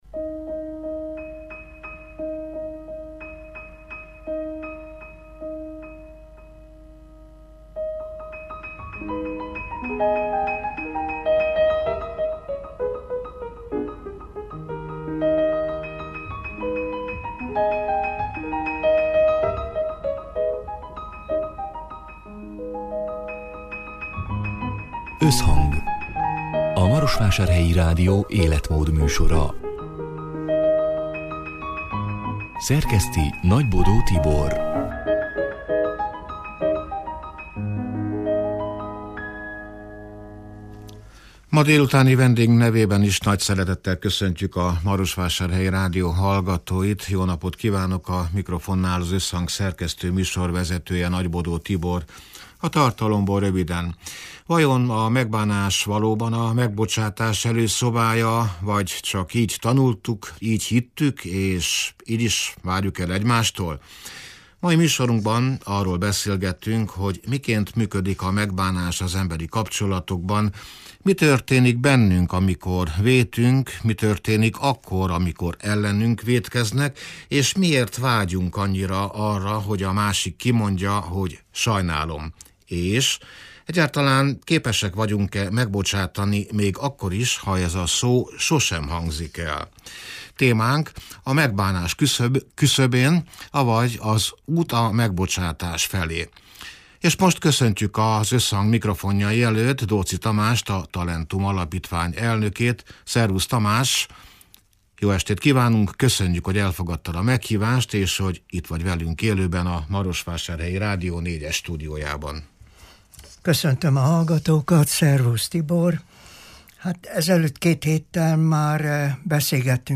(elhangzott: 2026. február 25-én, szerdán délután hat órától élőben)